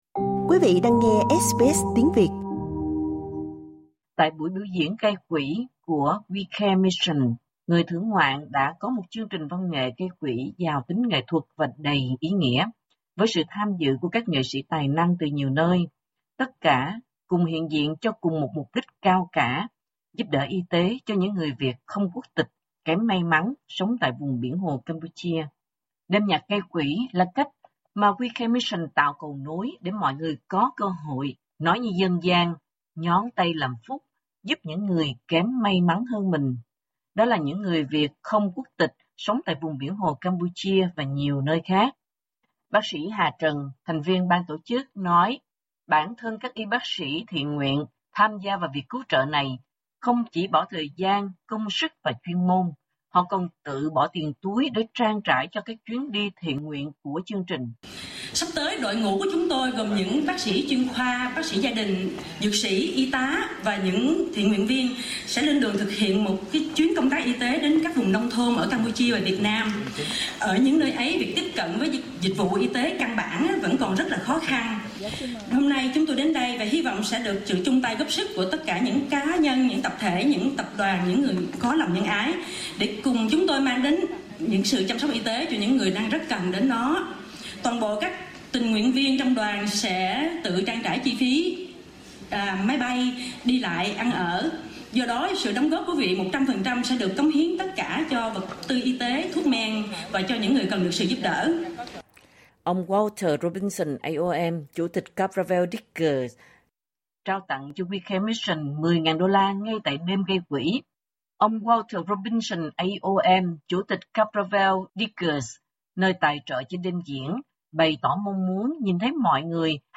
Đêm nhạc gây quỹ vì những người Việt không quốc tịch kém may mắn tại vùng Biển Hồ do WeCare Mission thực hiện vừa diễn ra vào tuần trước. Một đêm diễn với sự tham dự của các nghệ sĩ tài năng từ nhiều nơi đã đem đến cho người thưởng ngoạn một chương trình giàu tính nghệ thuật và đầy ý nghĩa.